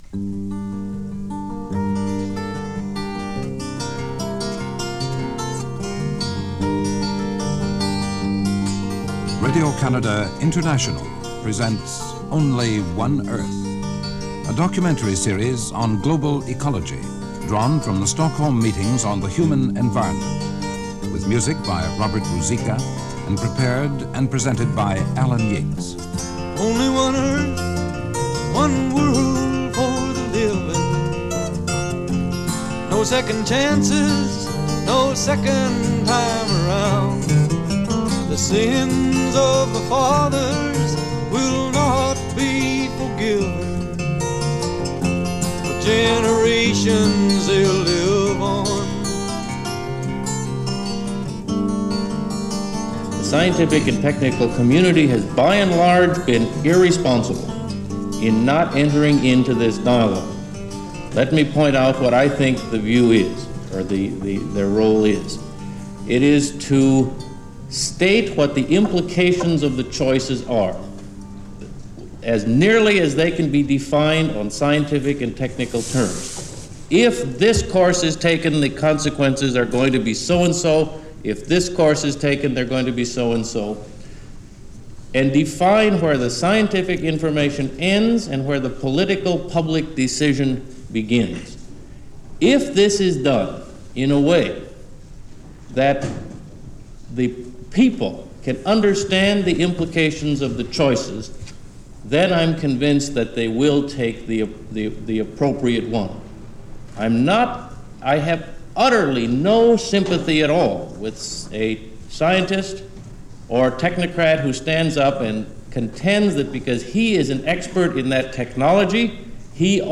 State of The Earth - 1972 - United Nations Conference On The Human Environment - Excerpts from The Stockholm Conference - June 1972.